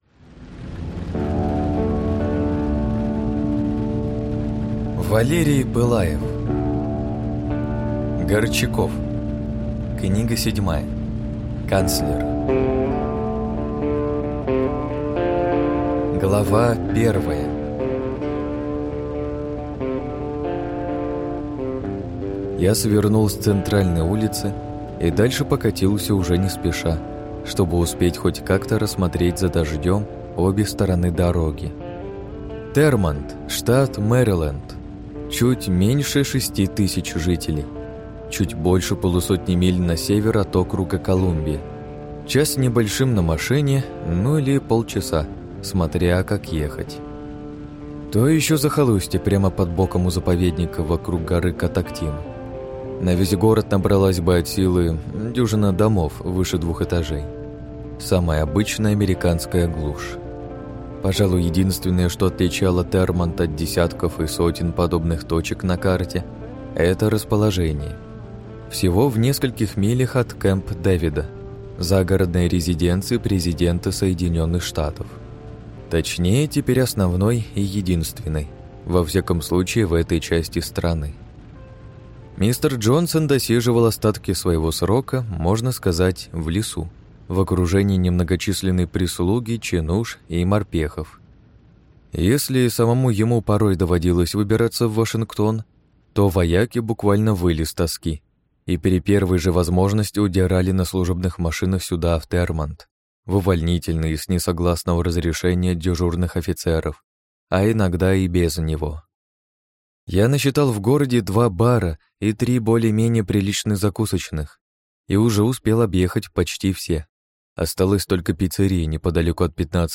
Аудиокнига Горчаков. Канцлер | Библиотека аудиокниг